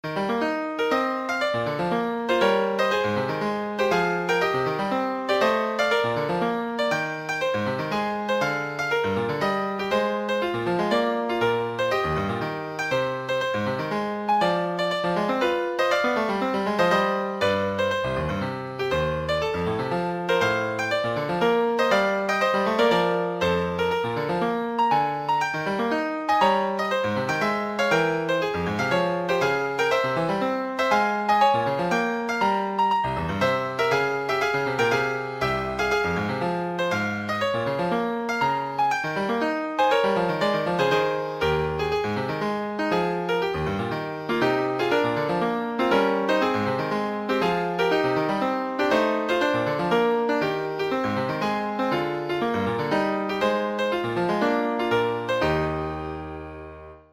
Piano version
Classical Piano